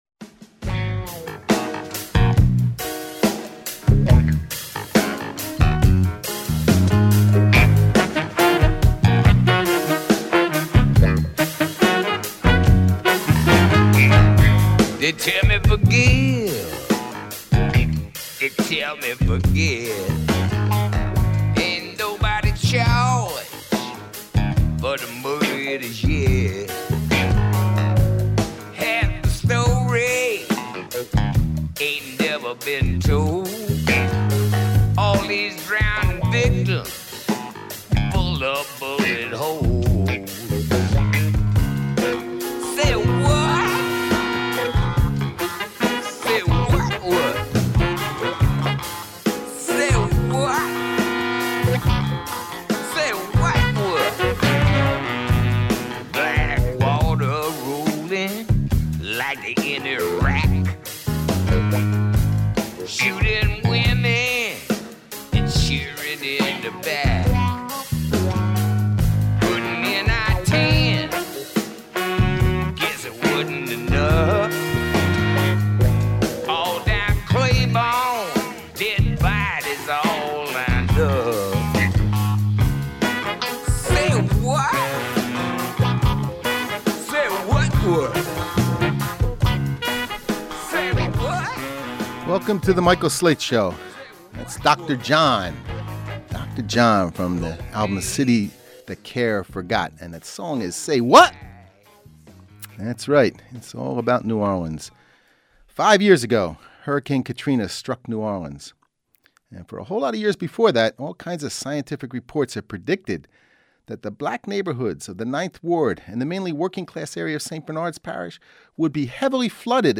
Program Type: Weekly Program